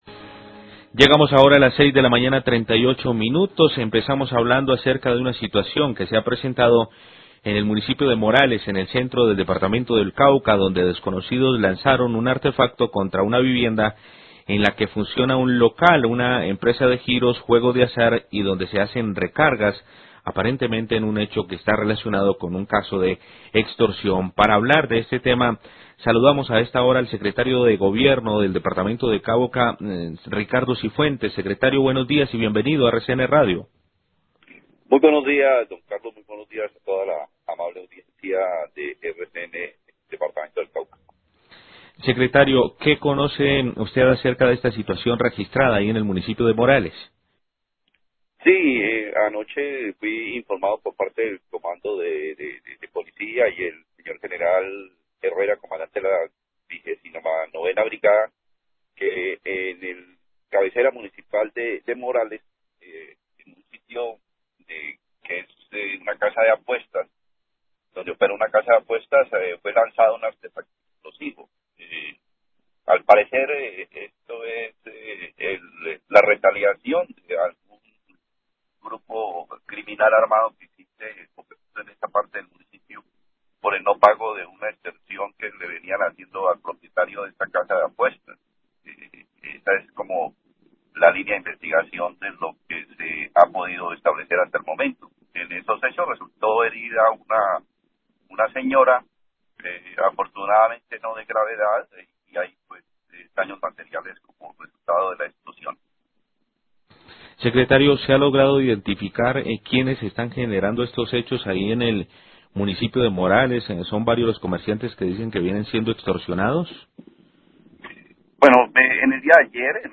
Radio
Secretario de Gobierno del Cauca, Ricardo Cifuentes, habla sobre atentado con granada a un puesto comercial en Morales y la suspensión del servicio de transporte fluvial en La Salvajina. Anuncia que se restablecerá el servicio de transporte a partir de hoy.